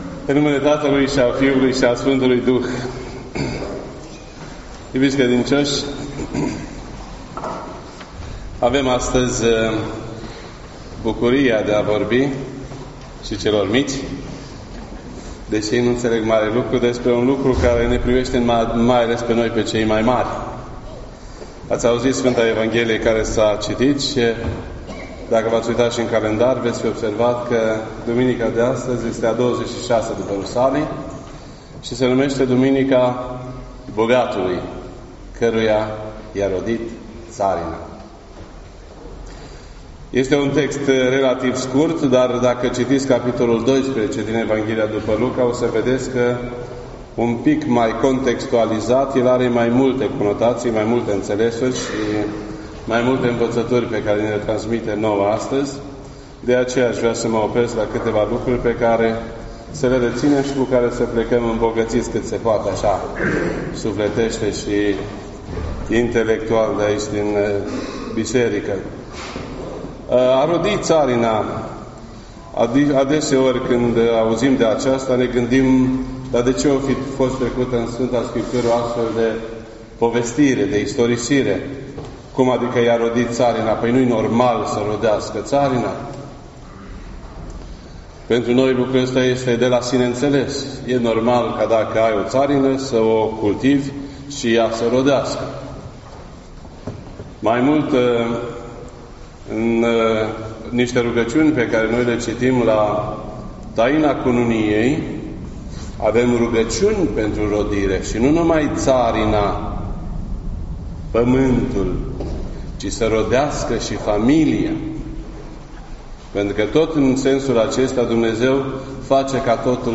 This entry was posted on Sunday, November 18th, 2018 at 12:46 PM and is filed under Predici ortodoxe in format audio.